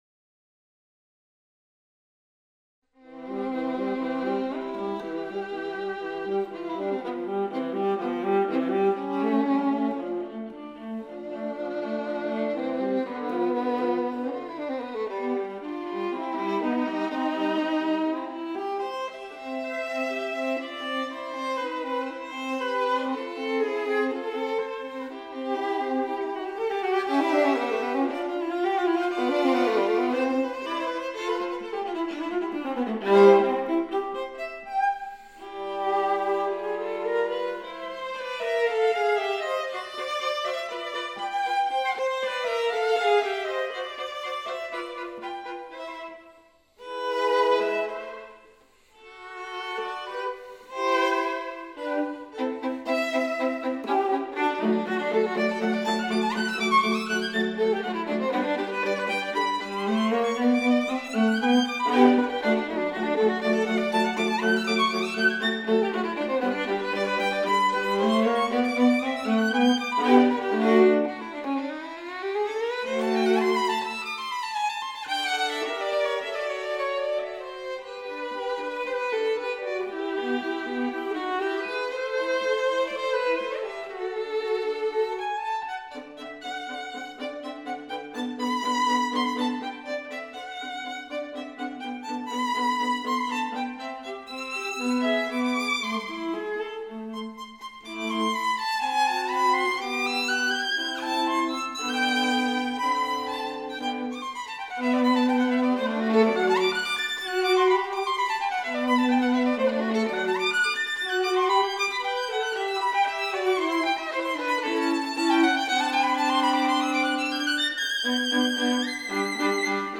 Tre Gran Duetti Concertanti op.7 per violino e viola